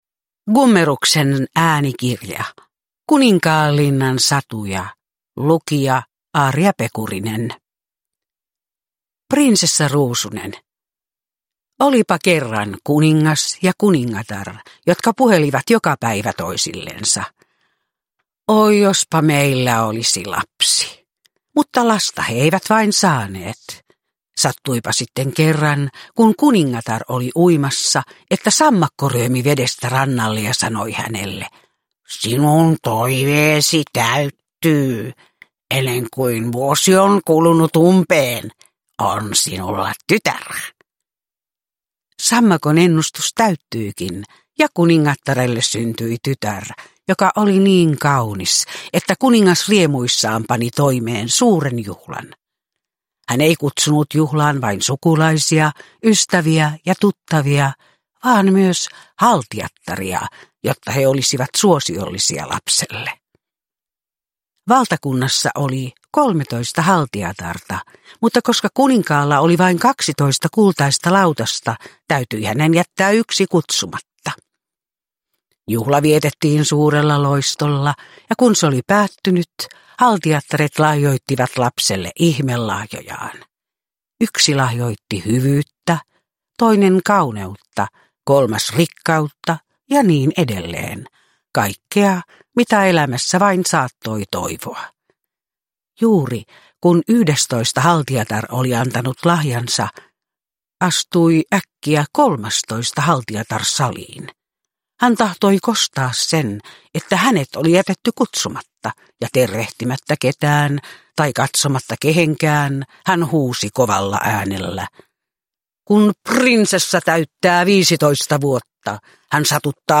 Kuninkaanlinnan satuja – Ljudbok – Laddas ner